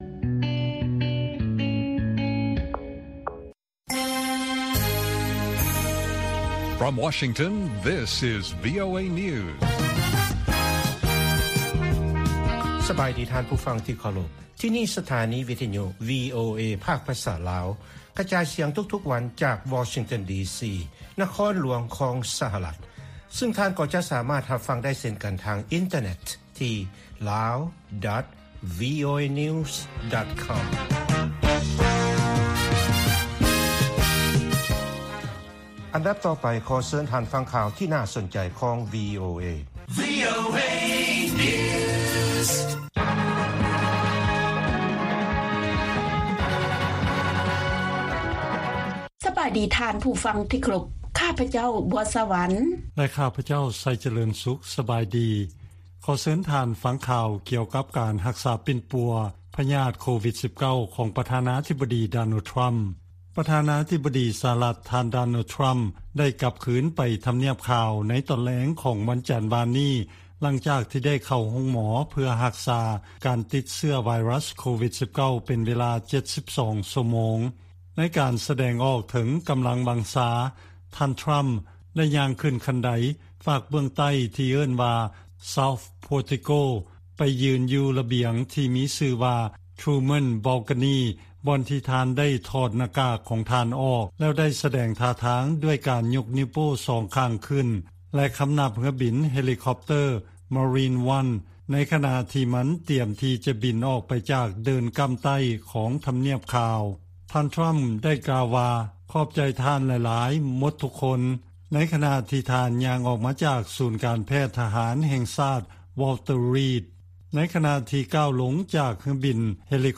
ວີໂອເອພາກພາສາລາວ ກະຈາຍສຽງທຸກໆວັນ. ຫົວຂໍ້ຂ່າວສໍາຄັນໃນມື້ນີ້ມີ: 1) ທາງການ ລາວ ຕ້ອງການຄວາມຊ່ວຍເຫຼືອ 7.6 ລ້ານໂດລາ ຈາກຕ່າງປະເທດ ສຳລັບນຳໃຊ້ໃນໂຄງການ ເກັບກູ້ລະເບີດ UXO. 2) ຜູ້ຢູ່ລັດວິສຄອນຊິນ ຢ່າງໜ້ອຍມີຄົນລາວ 1 ຄົນ ເສຍຊີວິດຍ້ອນໂຄວິດ-19 ໃນເດືອນແລ້ວນີ້. 3) ປ. ທຣໍາ ໃຊ້ຢາຫຍັງ ປົວພະຍາດໂຄວິດ -19 ຂອງເພິ່ນ?